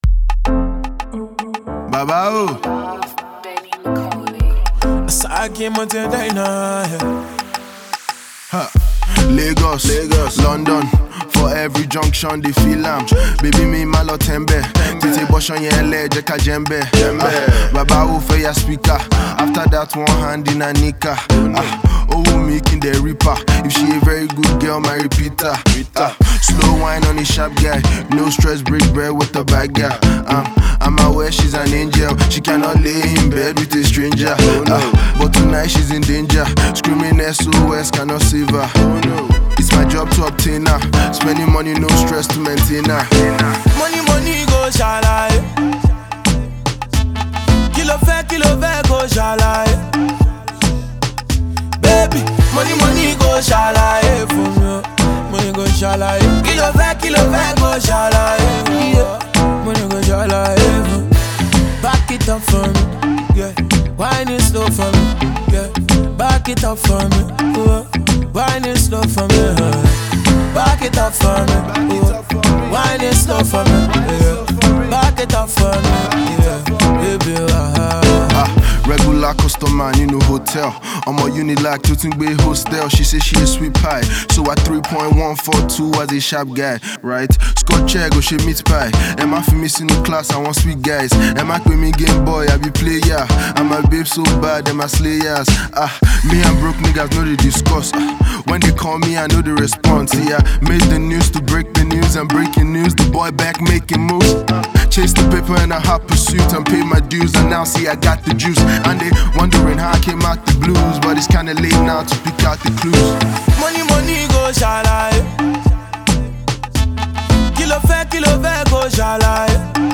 amazing vocals
young rapper’s artistry